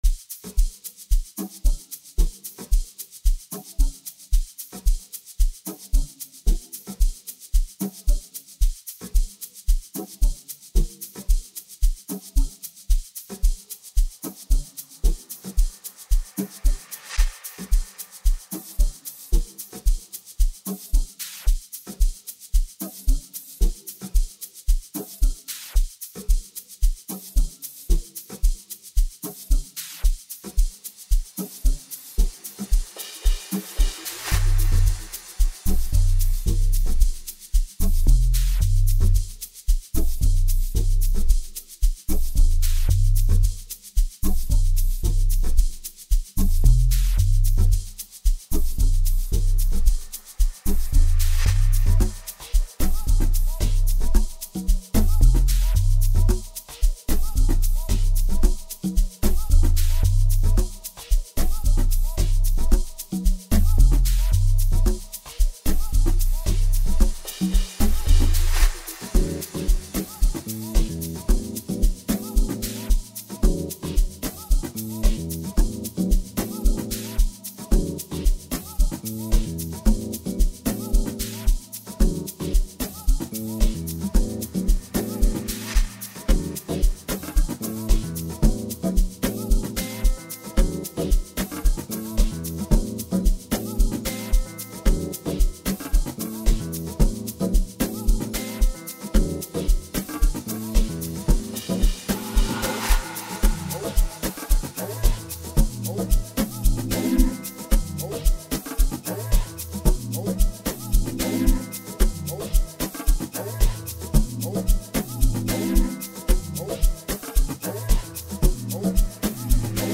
South African vocalist and musician